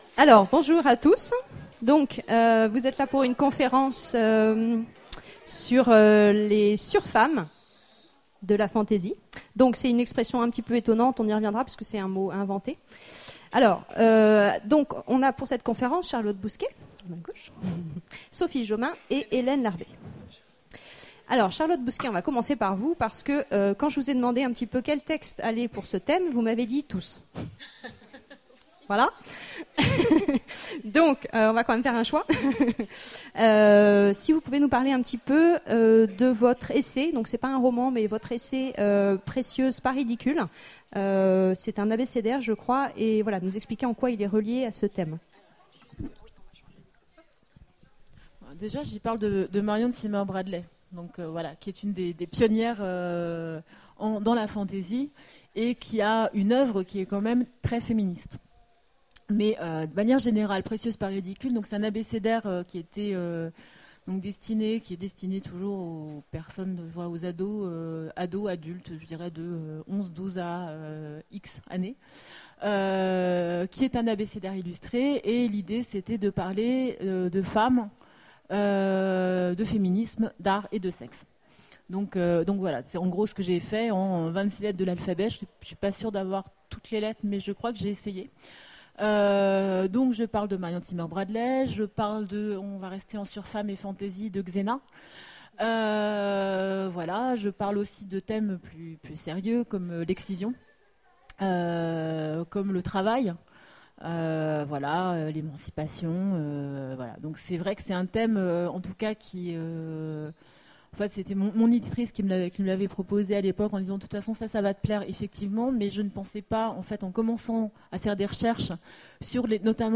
Imaginales 2015 : Conférence Après les surhommes